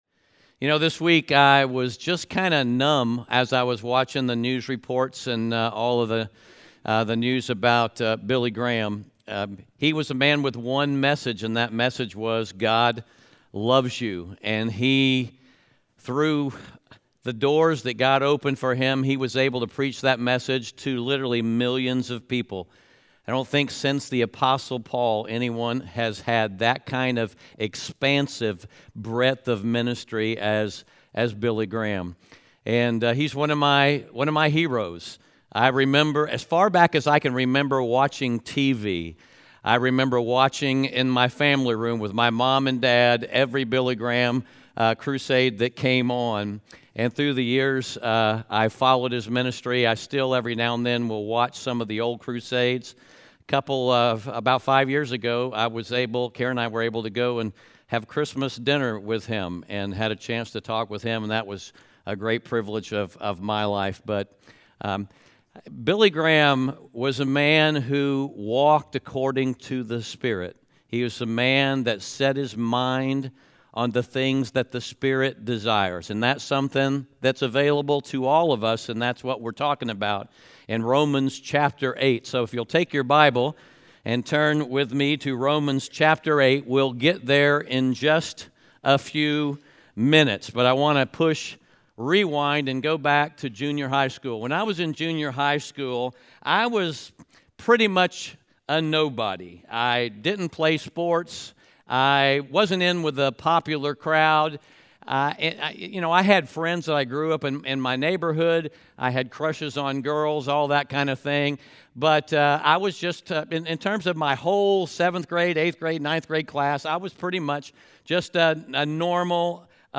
Romans 8:5-17 Audio Sermon Notes (PDF) Onscreen Notes Ask a Question *We are a church located in Greenville, South Carolina.